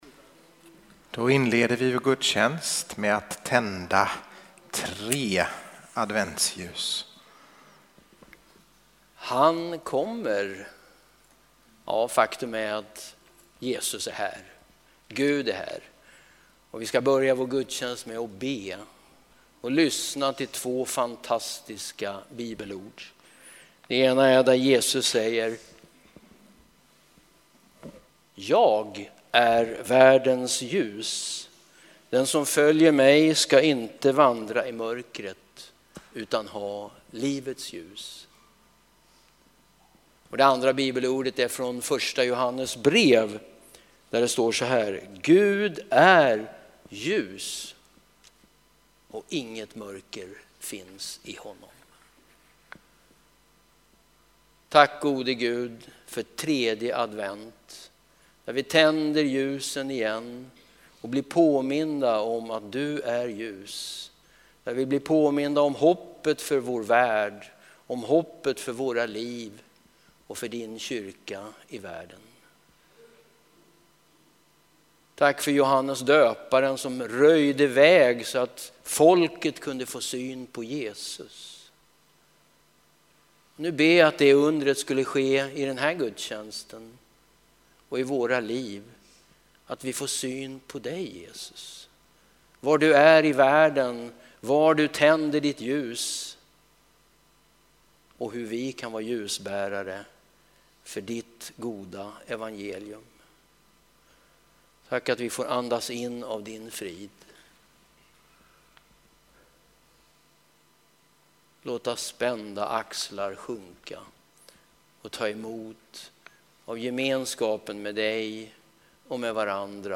Textläsning, bön och predikan Joh 8:12, 1 Joh 1:5, Ps 146:3-9, Matt 11:2-19